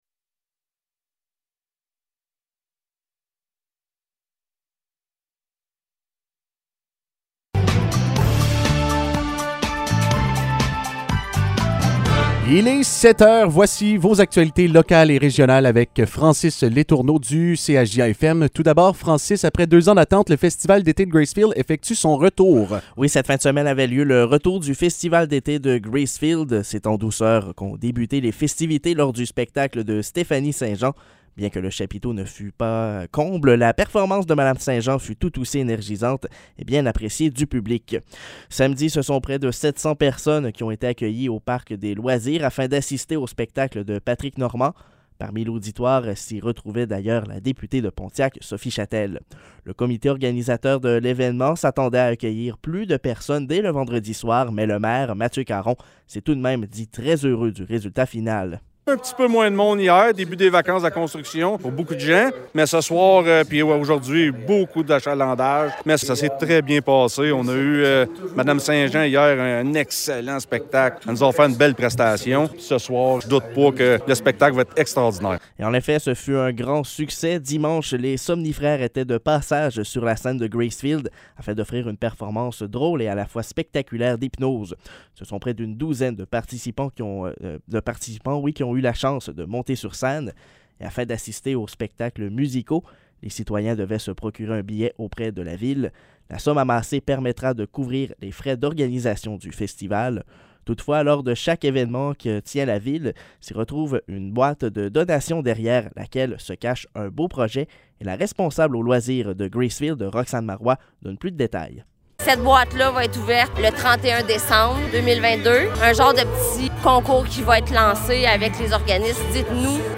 Nouvelles locales - 25 juillet 2022 - 7 h